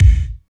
31.07 KICK.wav